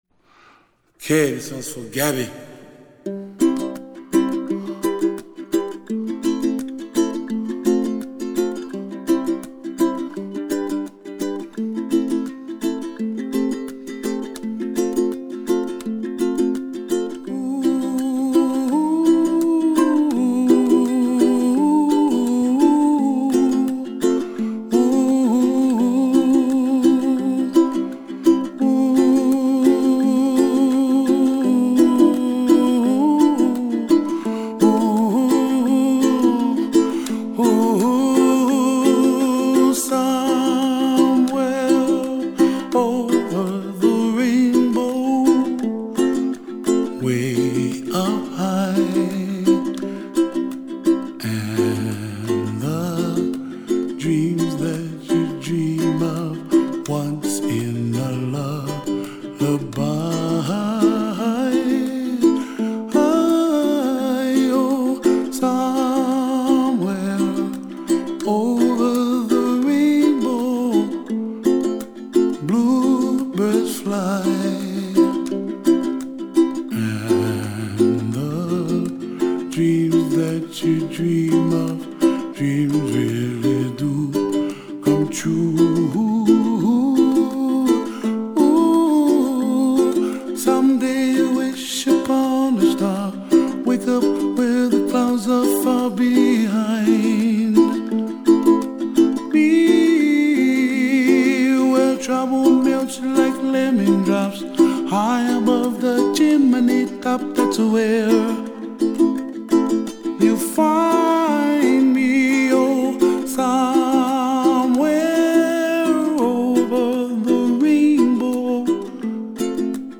灵魂乐的嗓音，你会发现，最虔诚的灵魂乐莫过于此。